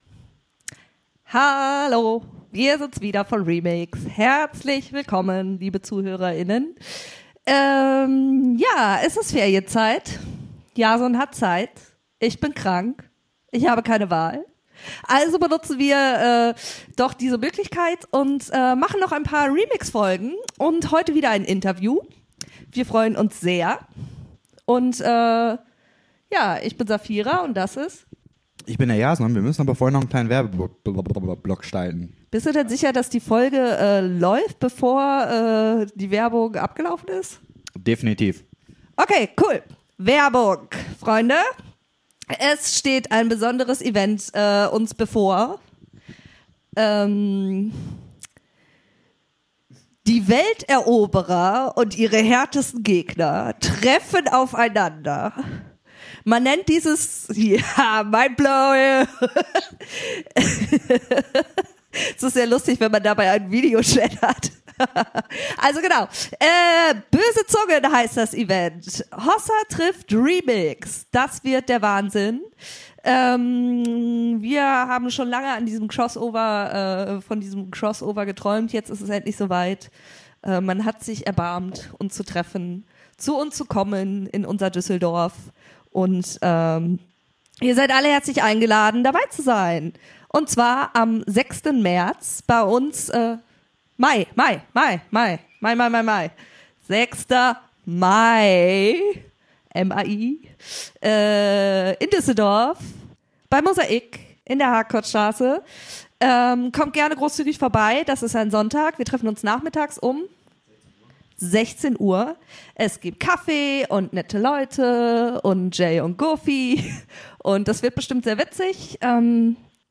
Episode 65 Wie geht man mit dem Tod um? Interview